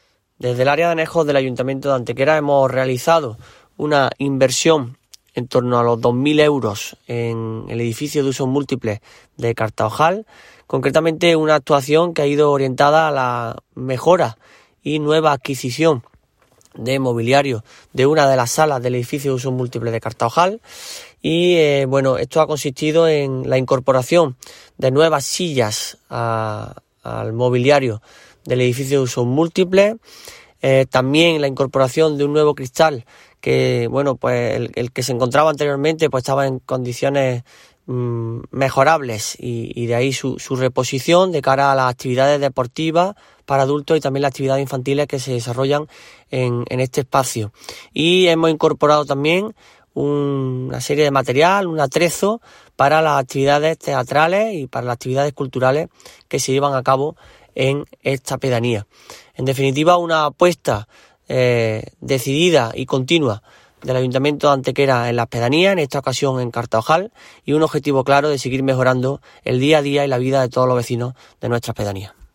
El concejal delegado de Anejos y Desarrollo Rural, José Manuel Fernández, informa de una nueva actuación encaminada a mejorar el día a día de los habitantes de nuestros anejos y pedanías, concretamente en esta ocasión hablando de Cartaojal.
Cortes de voz